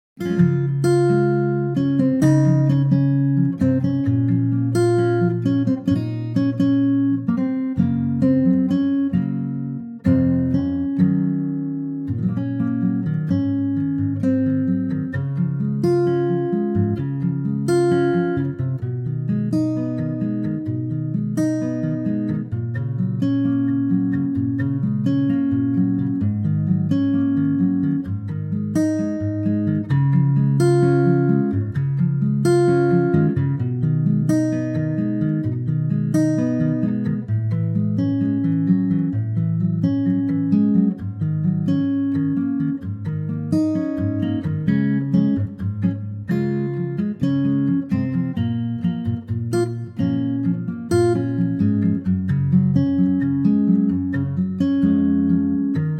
key Db 4:03
key - Db - vocal range - Ab to Db
Lovely acoustic guitar arrangement